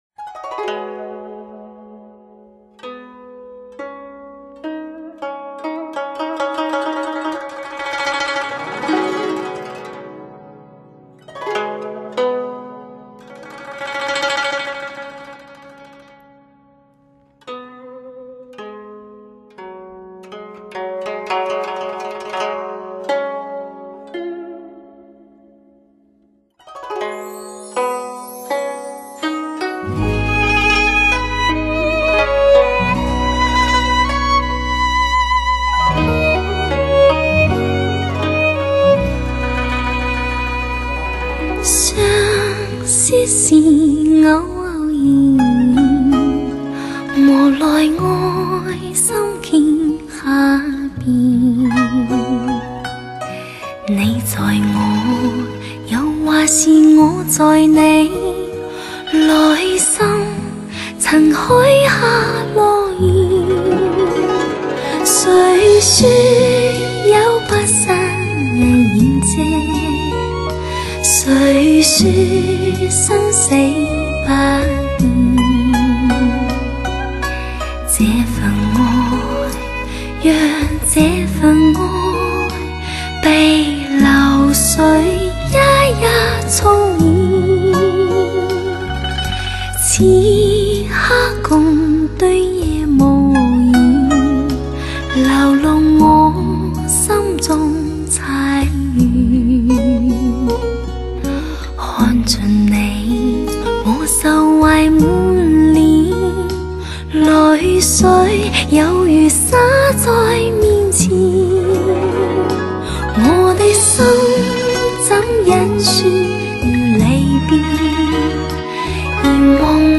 恬静柔美的声音再次感动你我，完美细致唯有你亲身体验。